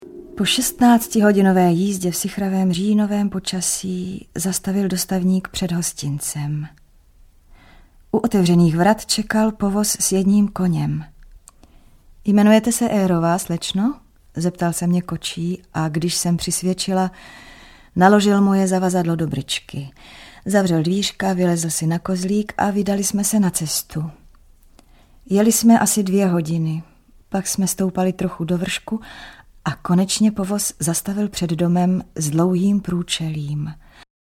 Jana Eyrová audiokniha
Audioverze klasického díla anglické literatury první poloviny 19. století. Nahrávka Českého rozhlasu z roku 1997.